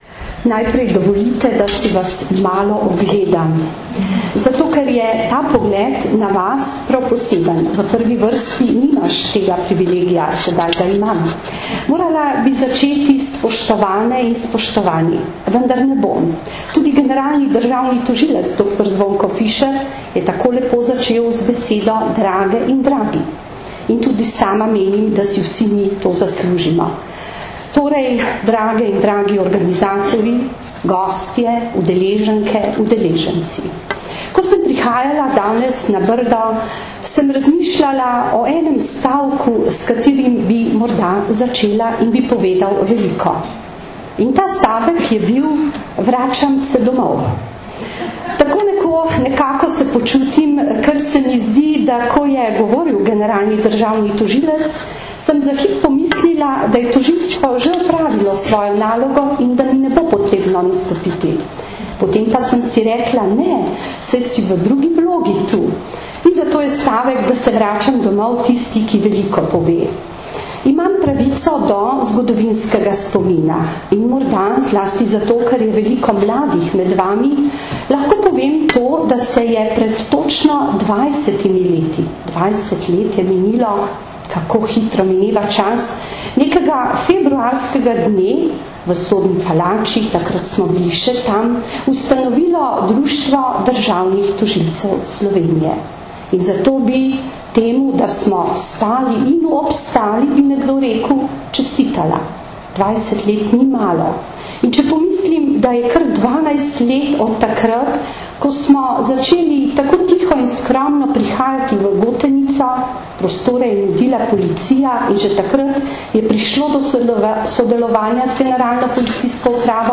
Na Brdu pri Kranju se je danes, 4. aprila 2013, začel dvodnevni strokovni posvet z naslovom Otrok v vlogi žrtve in storilca, ki ga že dvanajsto leto zapored organizirata Generalna policijska uprava in Društvo državnih tožilcev Slovenije v sodelovanju s Centrom za izobraževanje v pravosodju.
Zvočni posnetek nagovora varuhinje človekovih pravic Vlaste Nussdorfer (mp3)